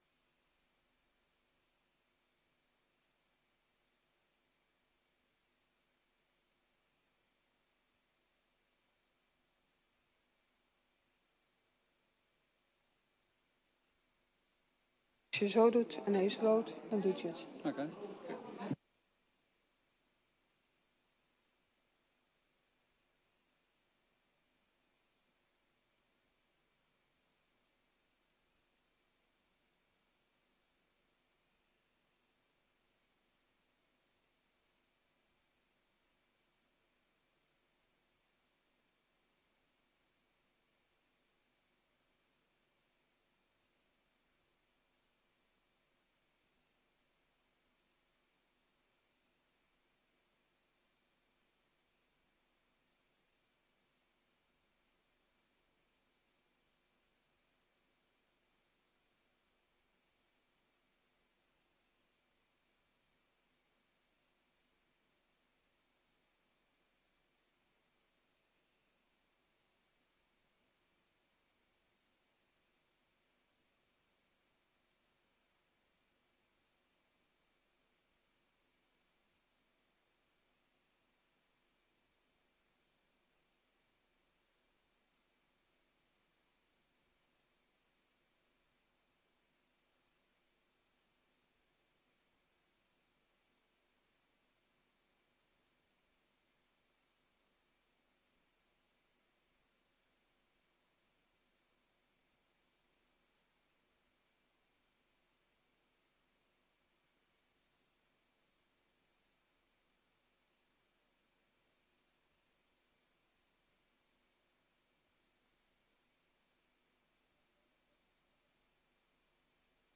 Gemeenteraad 20 maart 2025 20:00:00, Gemeente Woerden
Download de volledige audio van deze vergadering